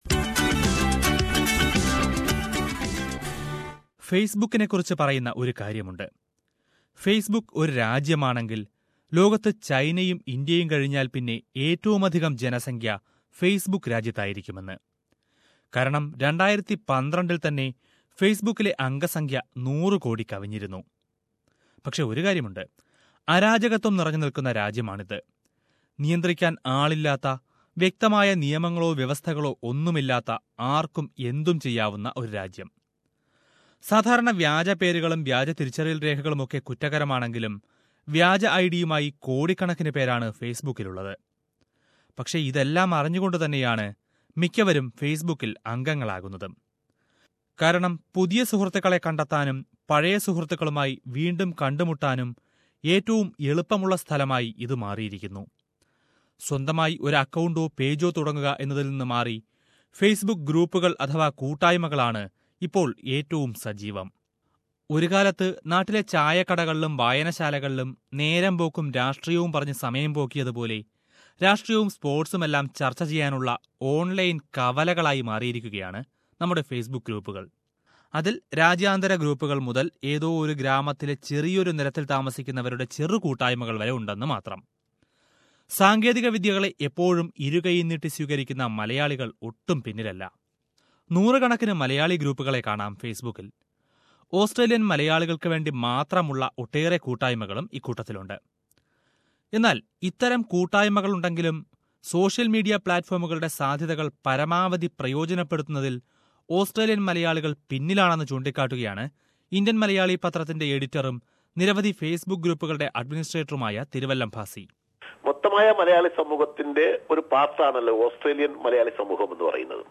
There are various malayalee community groups in Facebook around Australia which bring together the malayalees in and around the country. Listen to a report on the Malayalee FB groups in Australia